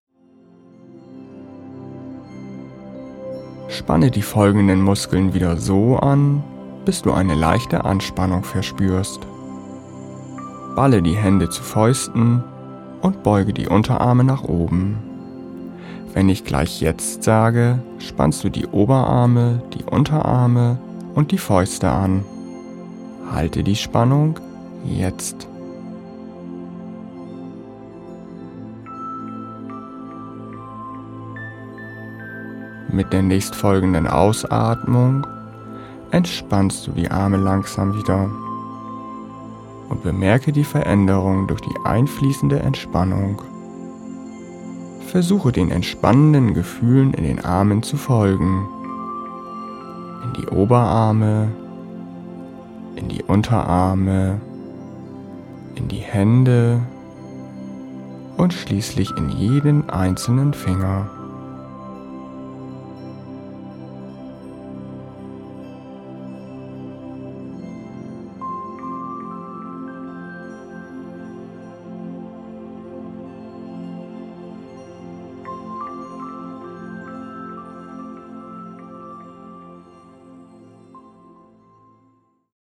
Progressive Muskelentspannung 4 Muskelgruppen mit Melodie
Hörprobe der Variante mit 4 Muskelgruppen oder auf